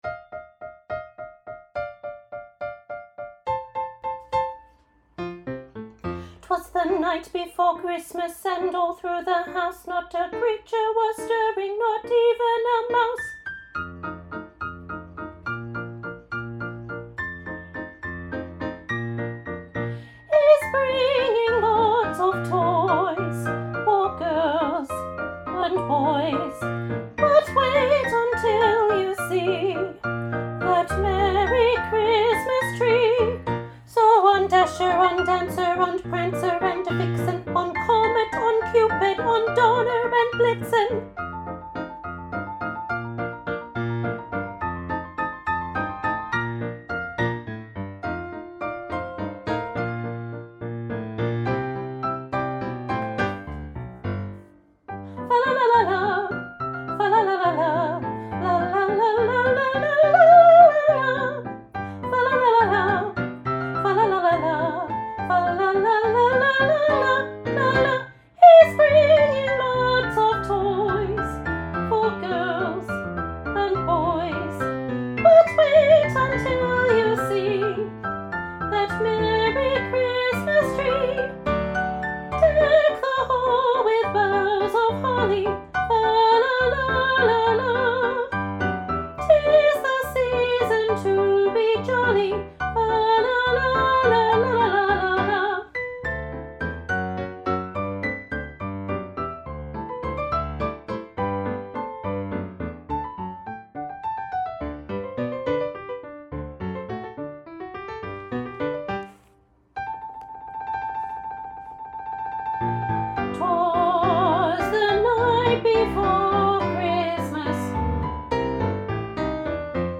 Junior Choir – Christmas Chopsticks, Soprano Part 1